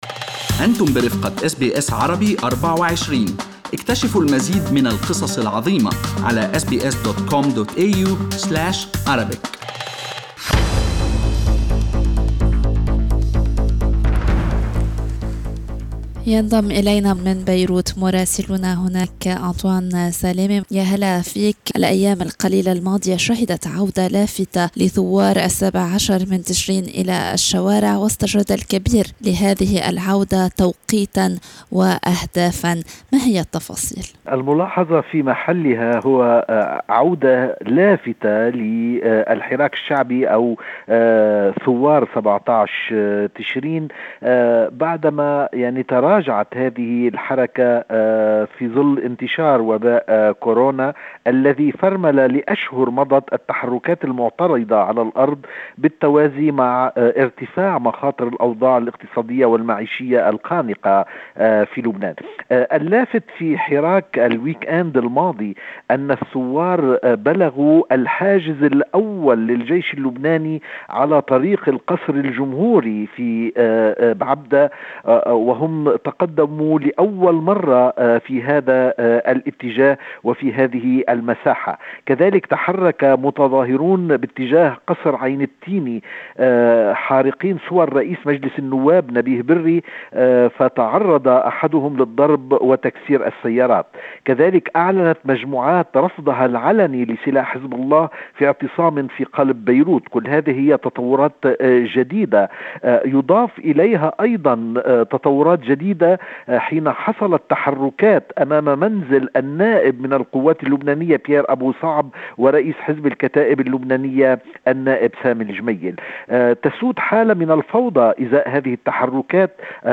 من مراسلينا: أخبار لبنان في أسبوع 02/06/2020